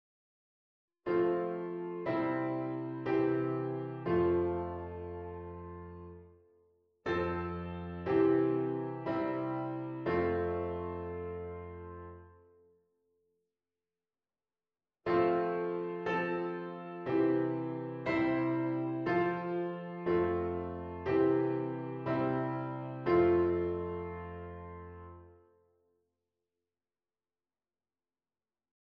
d. figuurtjes met een of meer omleggingen (waarbij natuurlijk altijd sprongen ontstaan).